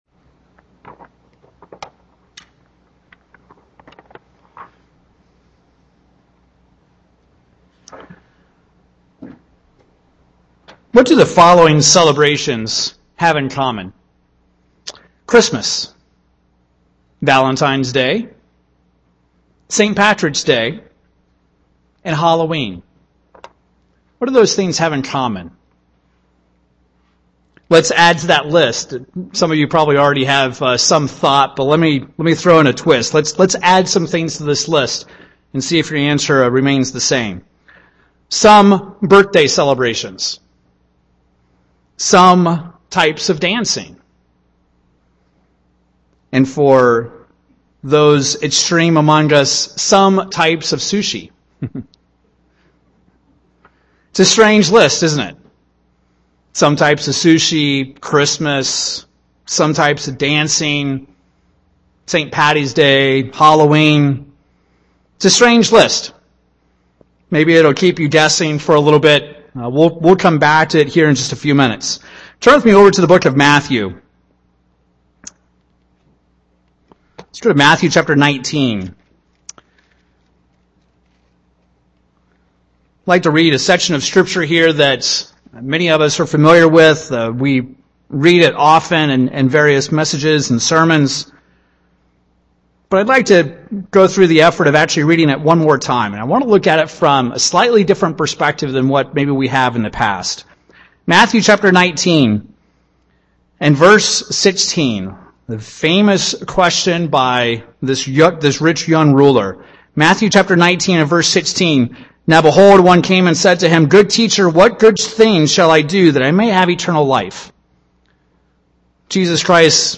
In this sermon we will explore the concept of blending black & white and living in the grey. Satan is a master-mind at influencing humans to take a little good and mix it with a little bad.